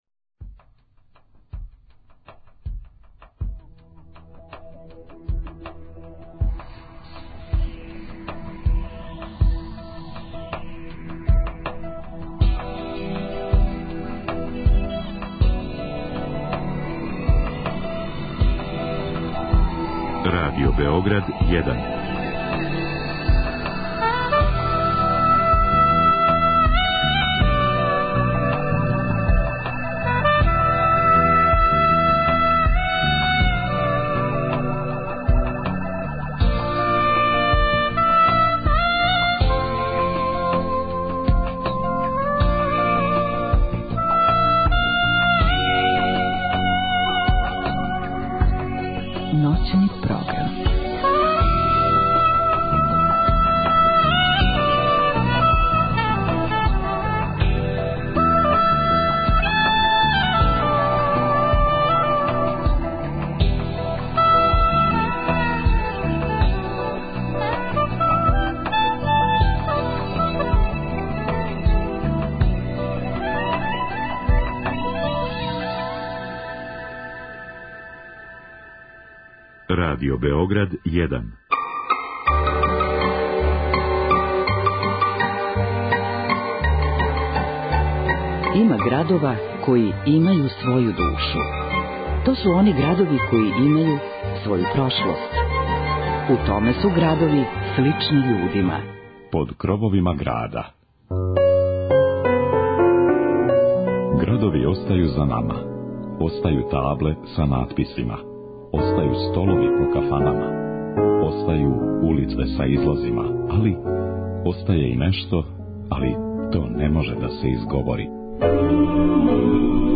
Ову ноћ обележиће кратке приче и занимљивости које су везане за поједине градове и живот у њима. Музичку нит чиниће староградска и новоградска музика, романсе и изворна народна музика.